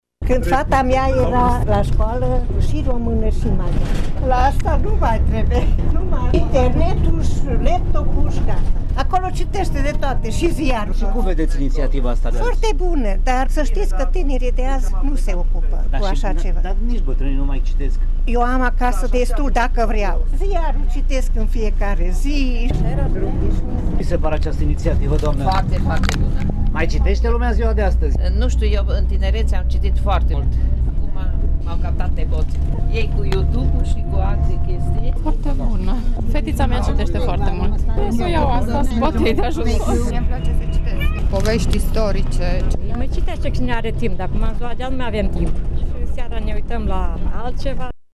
Unii dintre călători și-au adus aminte de acțiunile din anii trecuți, pe care le consideră extrem de benefice pentru educație. Cu toții au apreciat inițiativa, recunoscând că timpul pentru lectură s-a diminuat drastic în ultimii ani, în favoarea televizorului și a telefonului: